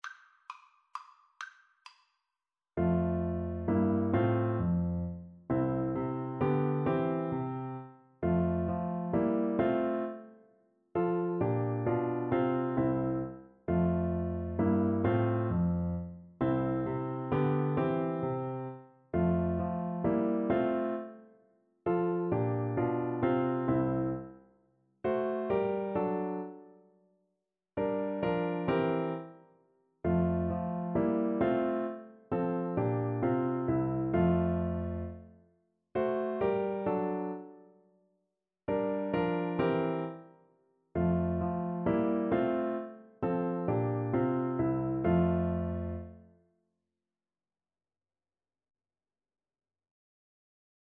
3/4 (View more 3/4 Music)
= 132 Allegro (View more music marked Allegro)
Classical (View more Classical French Horn Music)